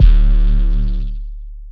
808 - DON.wav